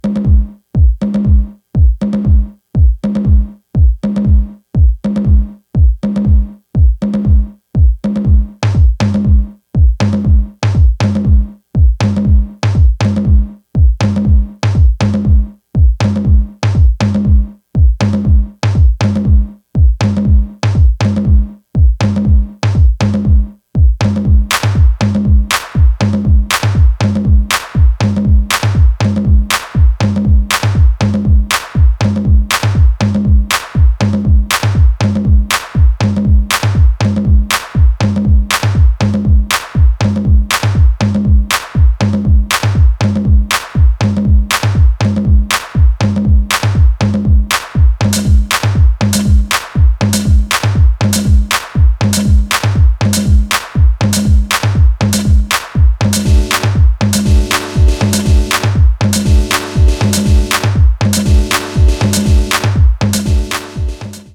a slapper in her own distinctive Detroit-style